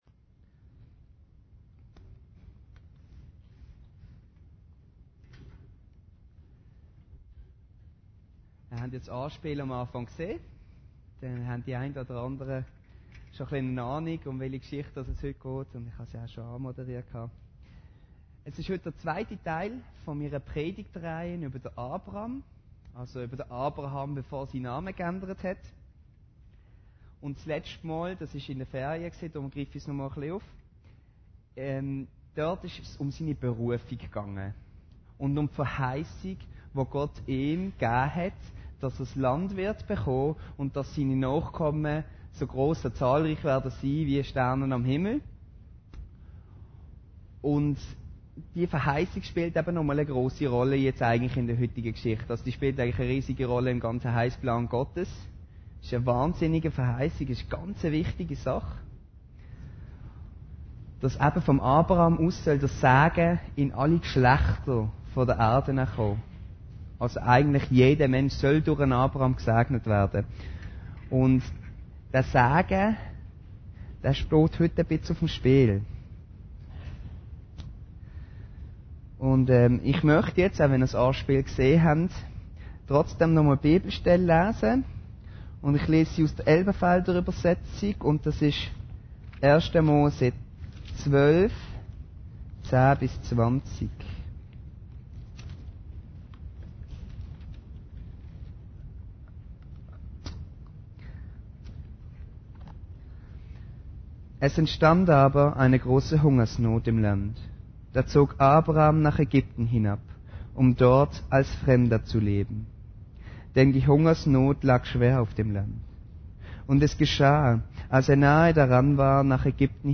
Predigten Heilsarmee Aargau Süd – Abram in Ägypten